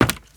High Quality Footsteps
Wood, Creaky
STEPS Wood, Creaky, Run 27.wav